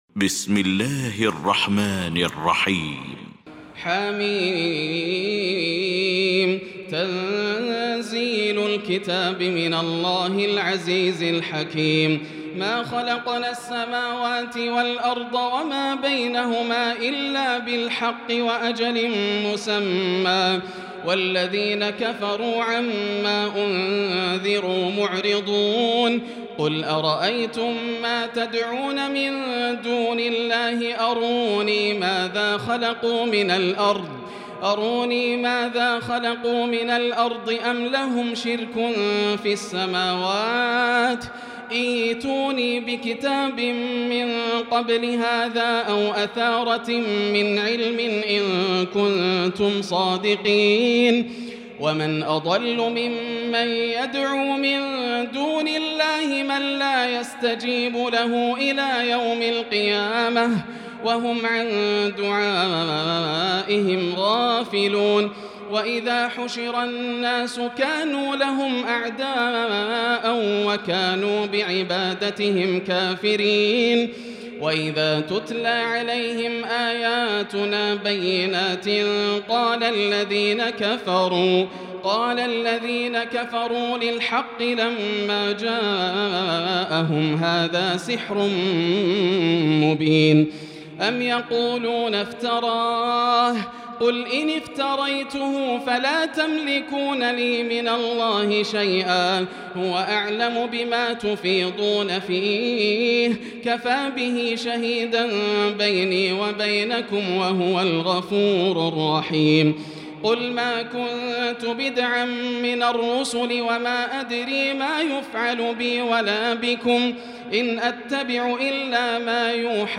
المكان: المسجد الحرام الشيخ: فضيلة الشيخ ياسر الدوسري فضيلة الشيخ ياسر الدوسري الأحقاف